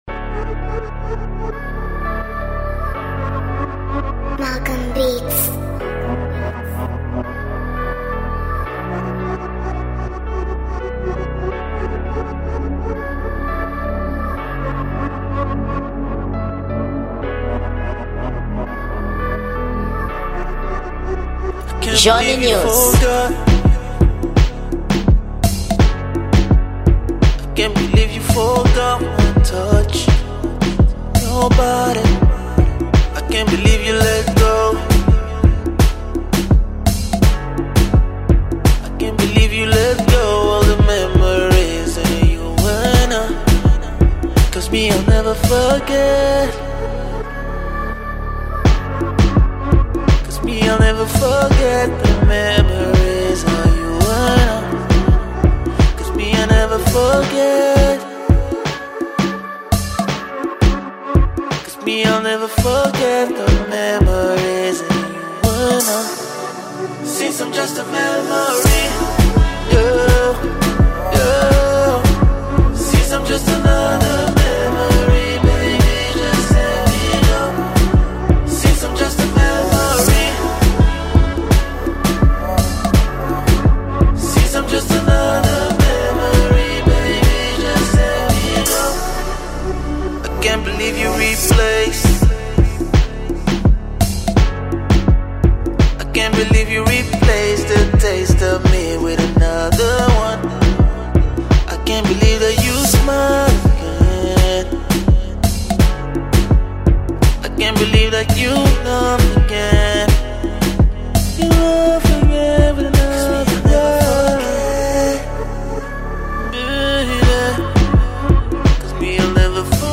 Gênero: Kizomba